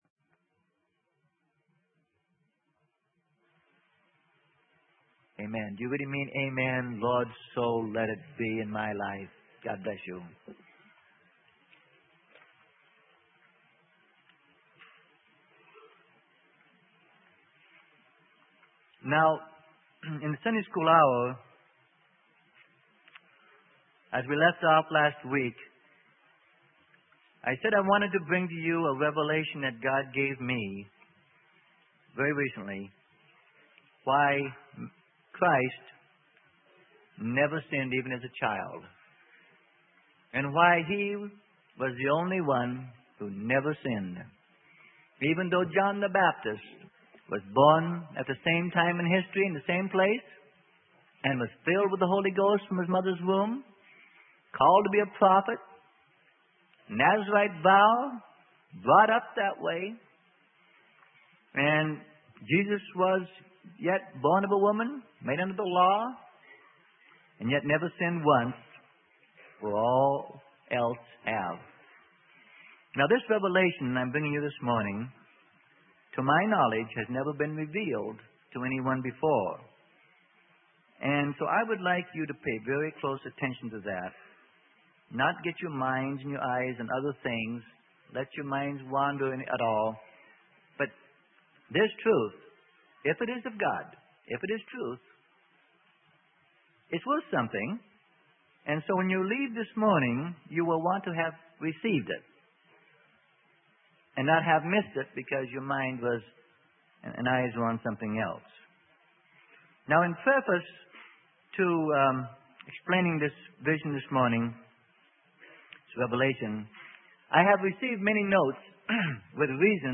Sermon: A Detailed Analysis of Fallen Man - Part 6 - Freely Given Online Library